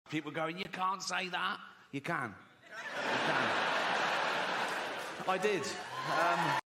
👩‍🏫 Twenty-six chairs scrape the floor.